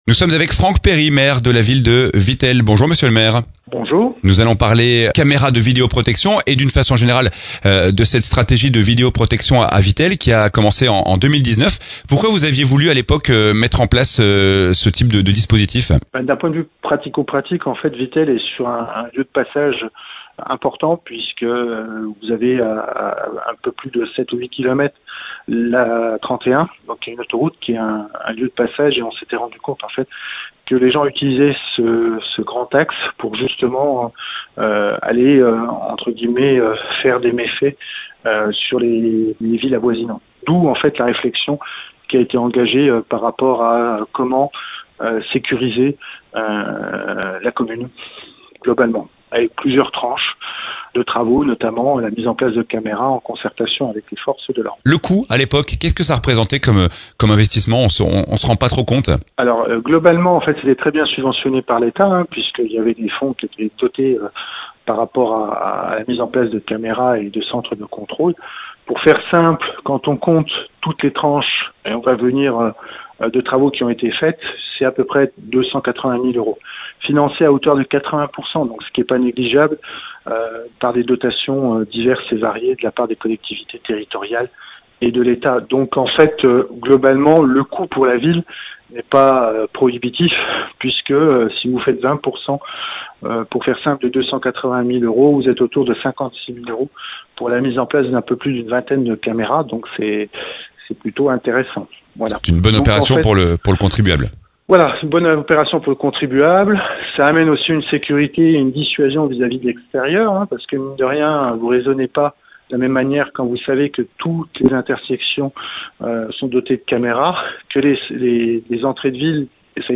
Il y a quelques jours, la Ville de Vittel a présenté son nouveau plan de vidéoprotection. On en parle avec son maire, Franck Perry.